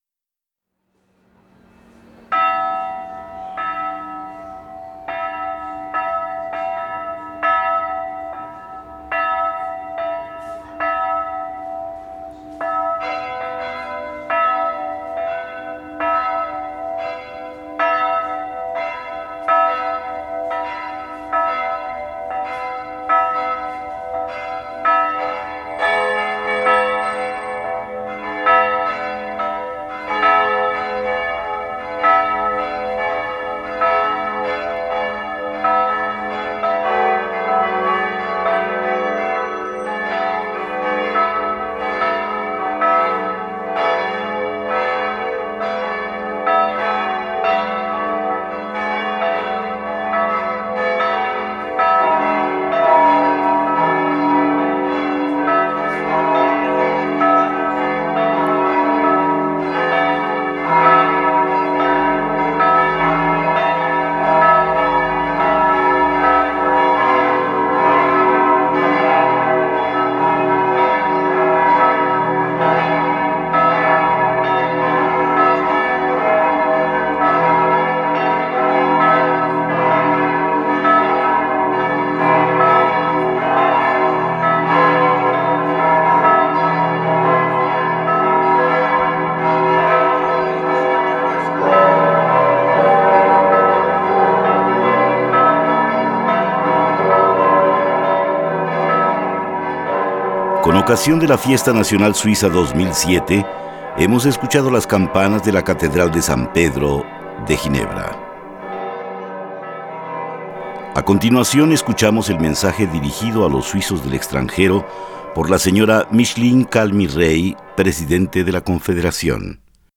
Con motivo del 1 de agosto, Fiesta Nacional de Suiza, la presidenta Micheline Calmy-Rey se dirige a los compatrioras que viven lejos de su patria. Las campanas de la Catedral de San Pedro de Ginebra y el himno nacional acompañan la alocución.